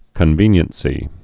(kən-vēnyən-sē)